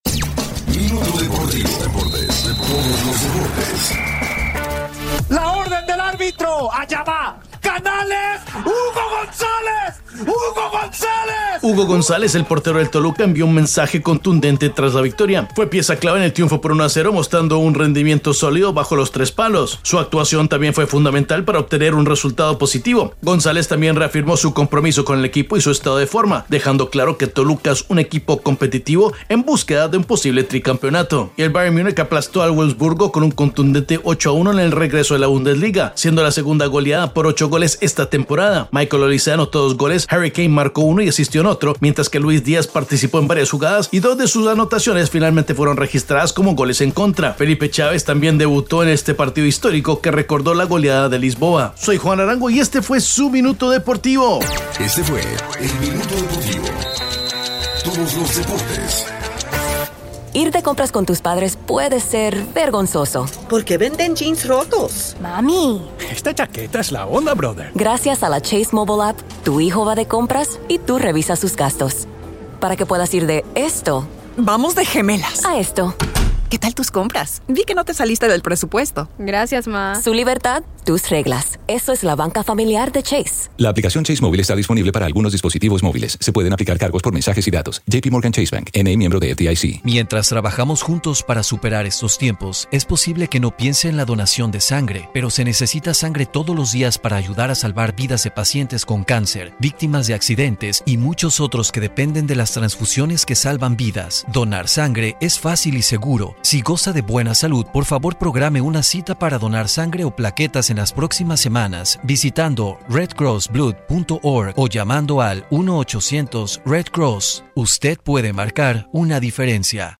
Minuto Deportivo delivers quick-hitting daily sports updates in just 60 seconds.